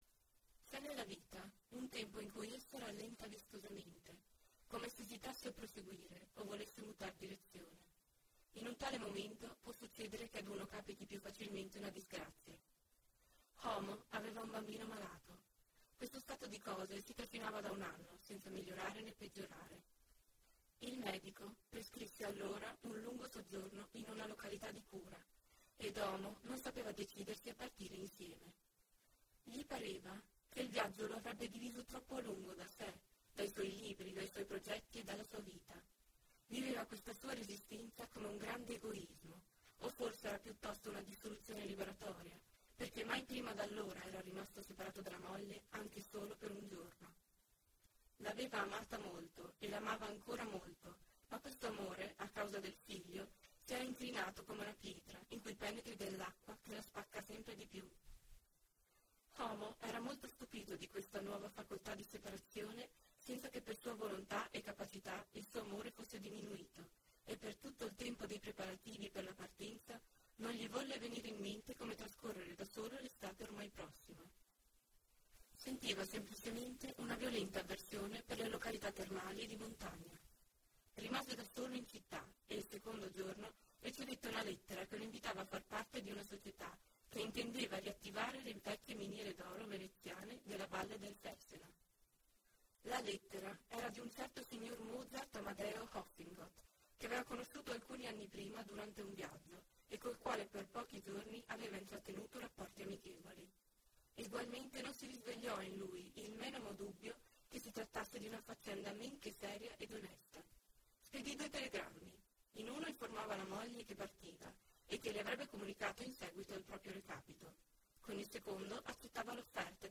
Audiolibro Grigia intero mp3.mp3